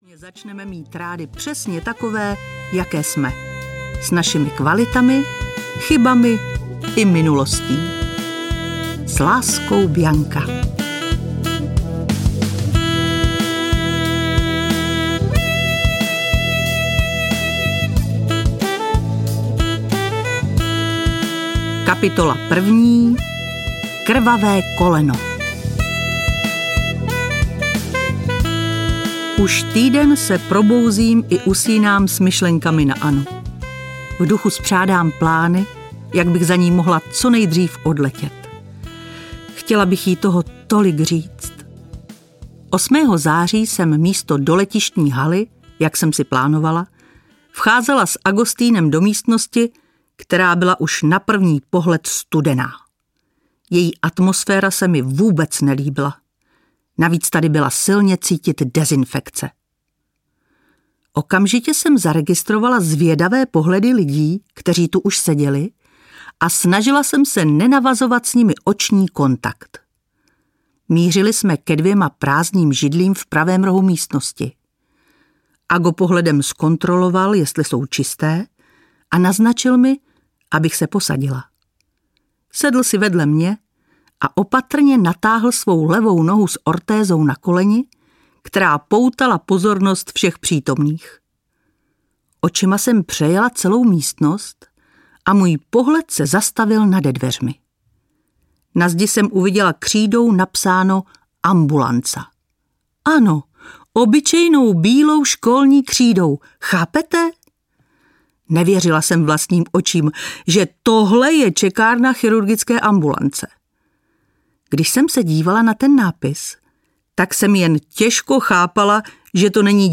Srdce nemá vrásky audiokniha
Ukázka z knihy